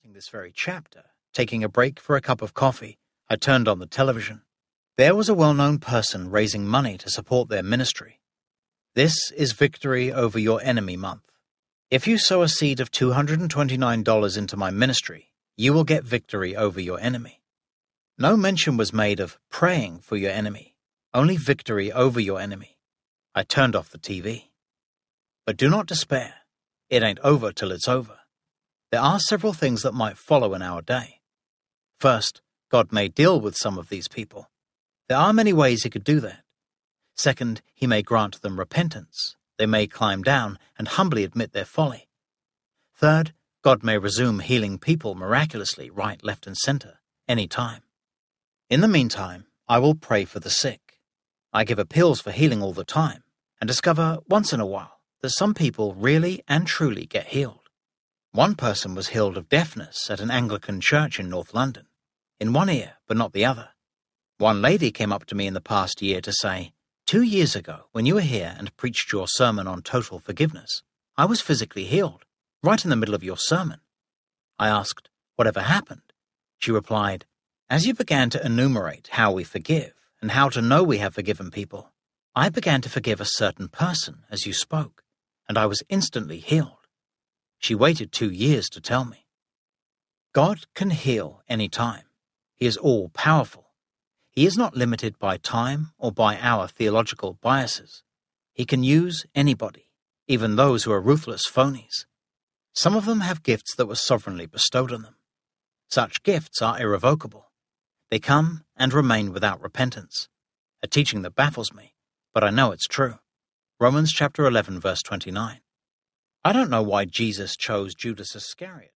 It Ain’t Over Till It’s Over Audiobook
5.5 Hrs. – Unabridged